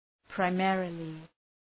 Προφορά
{praı’meərəlı}